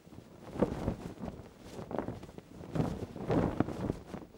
cloth_sail6.R.wav